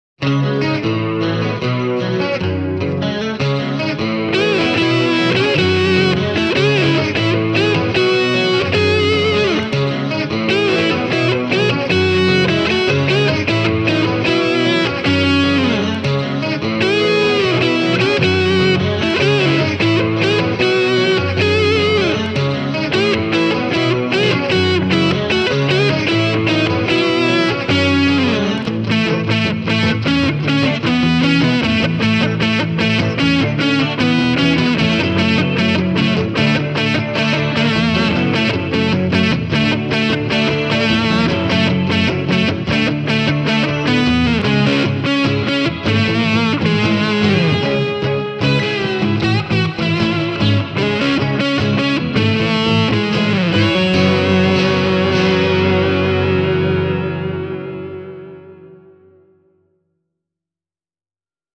And here’s the demo song – with and without the other instruments: